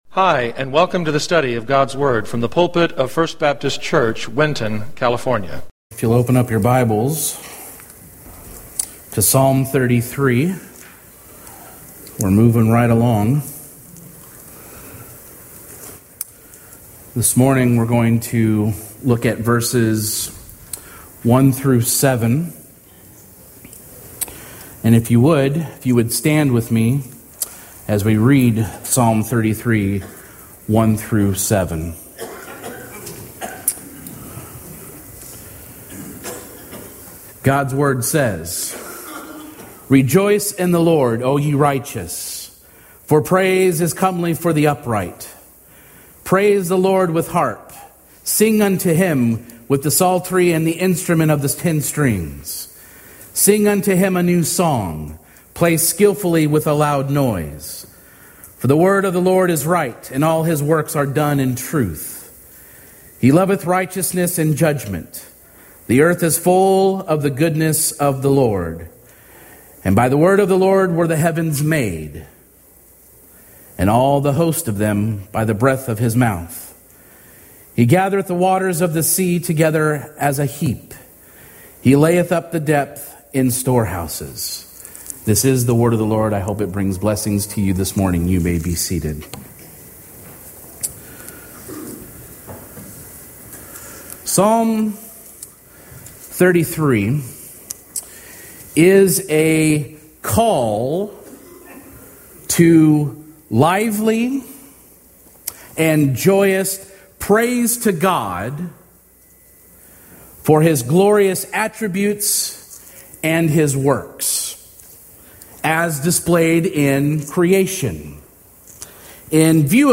Sermons | Winton First Baptist Church